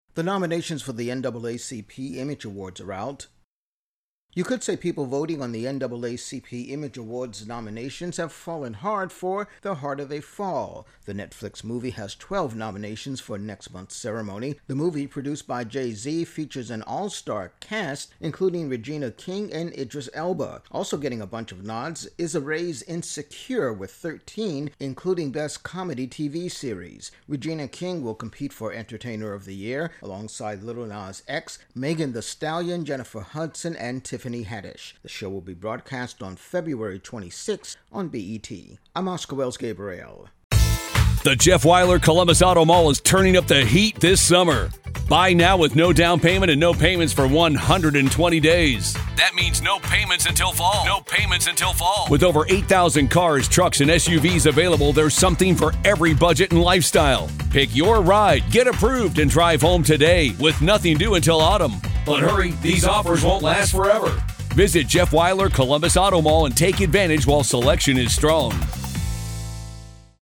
Intro+voicer on NAACP Image Awards nominations